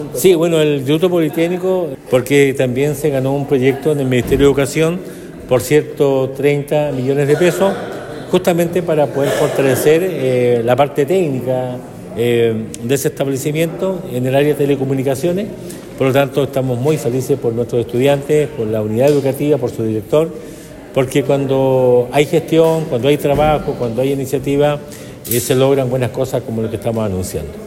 Serán alrededor de 130 millones de pesos los que recibirá el establecimiento, lo que fue destacado por el Alcalde Emeterio Carrillo, quien señaló que esto se debe al buen desempeño que realizan los docentes y directivos del liceo.